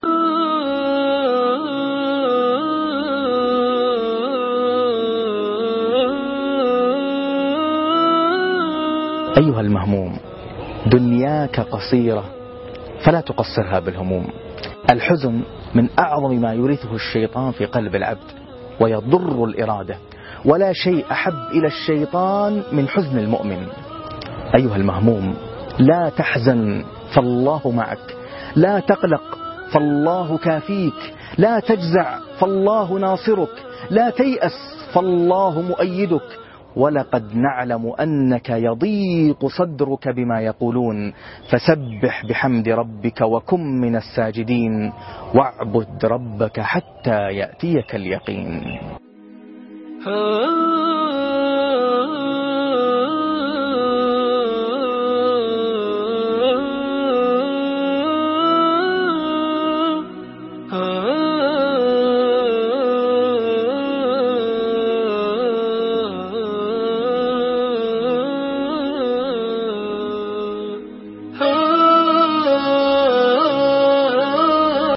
أيها المهموم لا تحزن (14/7/2014) تغريدات قرآنية - القاريء ناصر القطامي